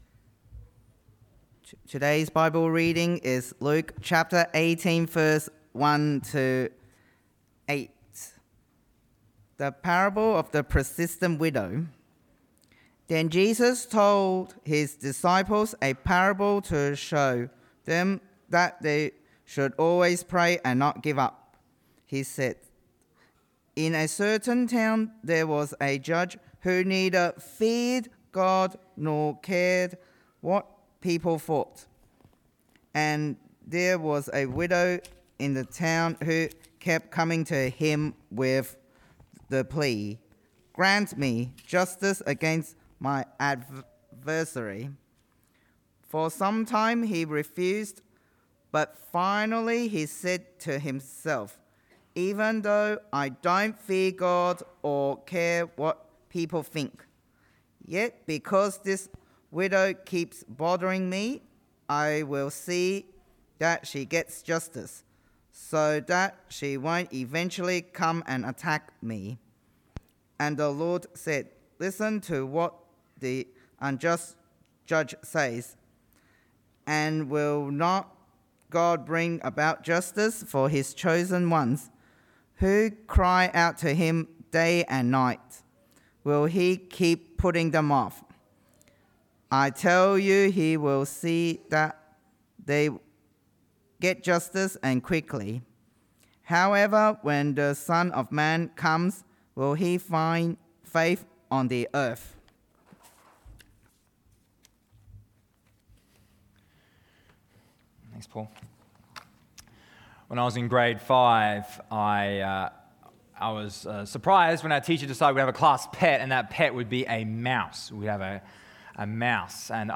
Sermons – Cairns Presbyterian Church